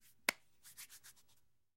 Звуки рукопожатия
Звук привітання ручним струшуванням